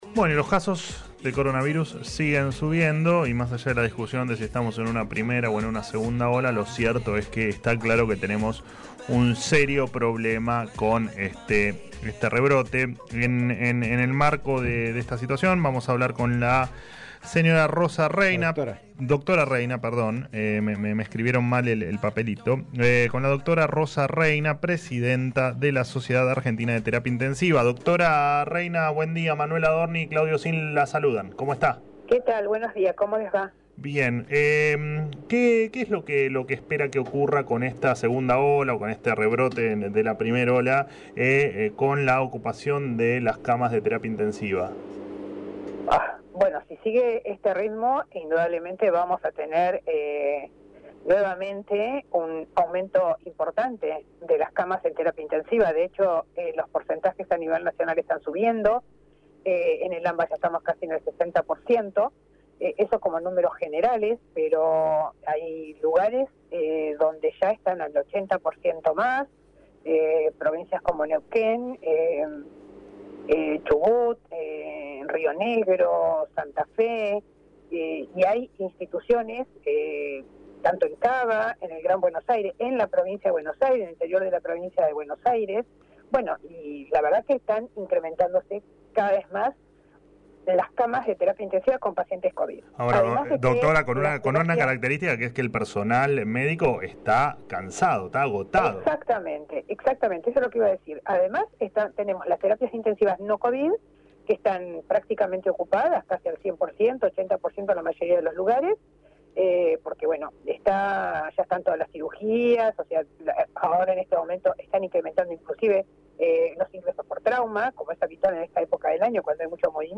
dialogó en Alguien Tiene que Decirlo sobre el nivel de ocupación de camas en Unidades de Terapia Intensiva y se refirió al estado en el que se encuentra el personal de salud tras un año de pandemia.